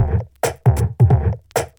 Original creative-commons licensed sounds for DJ's and music producers, recorded with high quality studio microphones.
distorted_techno_break_-_pluck_qLR.wav